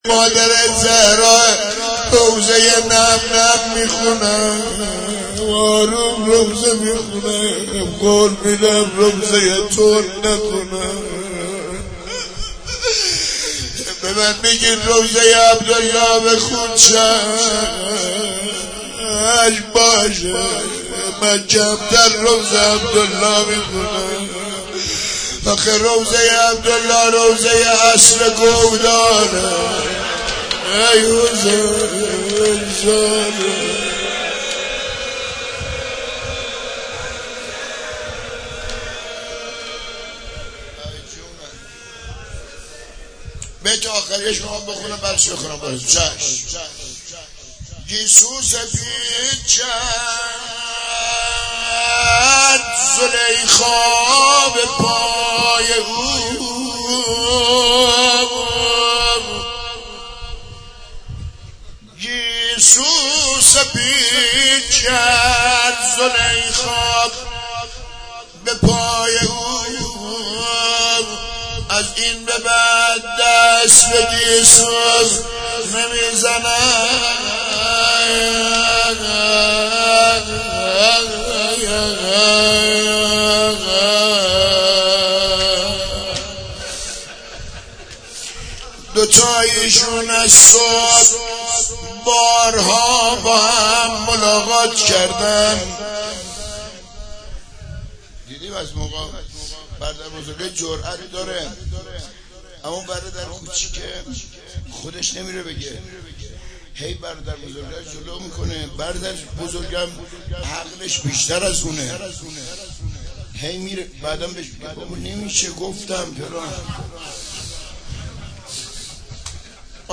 روضه حضرت قاسم
مناسبت : شب پنجم محرم
قالب : روضه